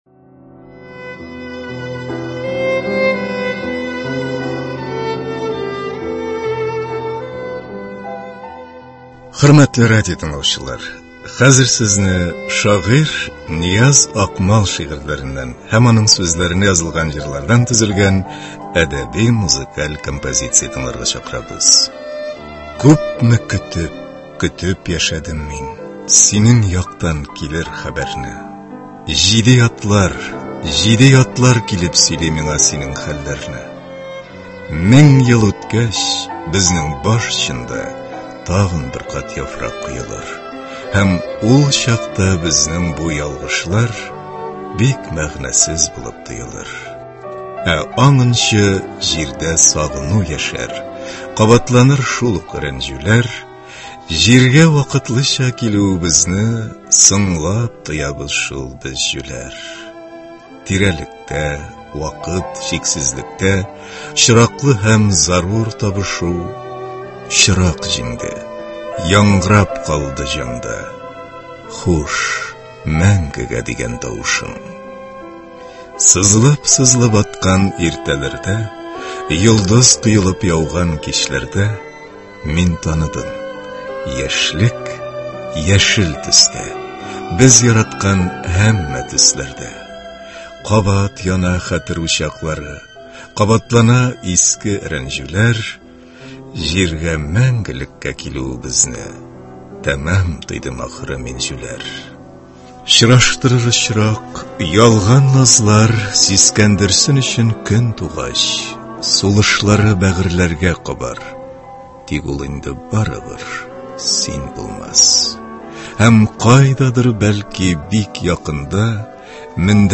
шигъри-музыкаль композиция